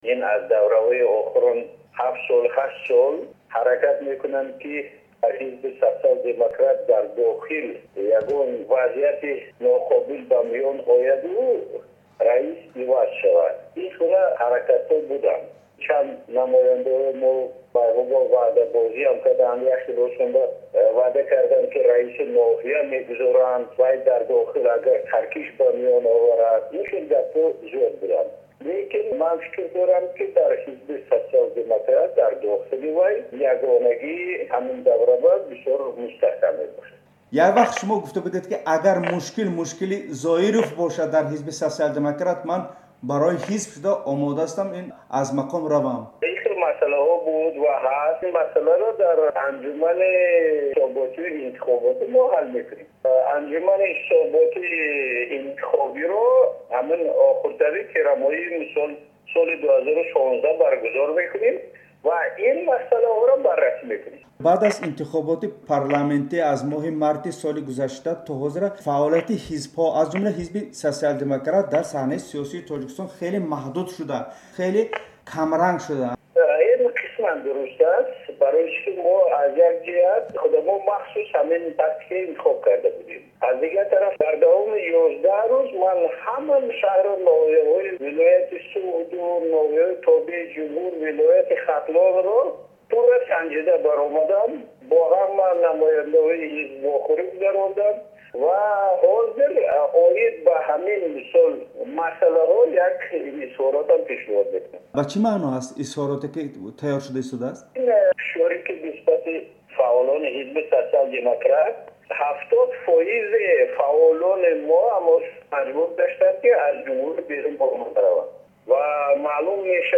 Гуфтугӯ бо Раҳматилло Зоиров